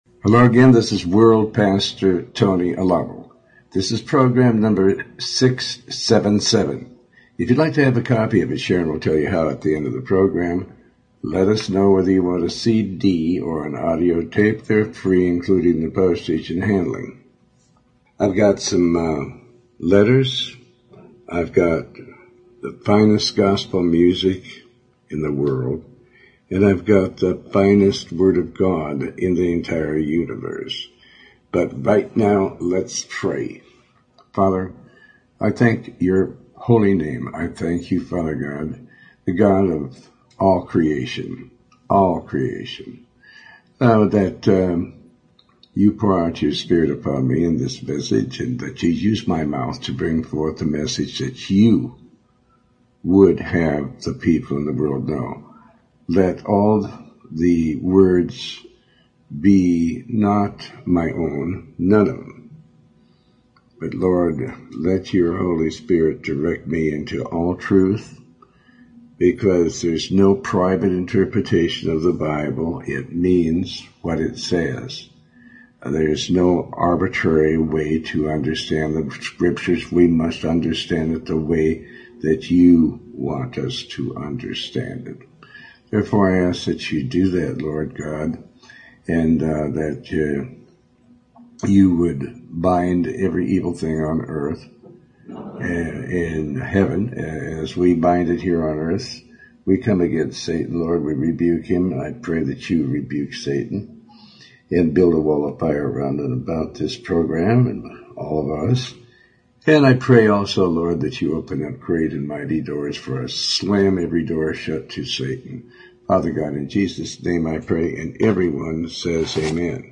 Talk Show Episode, Audio Podcast, Tony Alamo and Elvis Presley the very best gospel singer in the world.